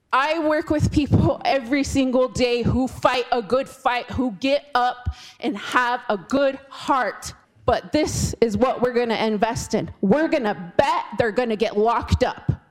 Democratic Rep. Peri Pourier (pooh-yer) from Rapid City questioned whether the state should examine the root causes of incarceration.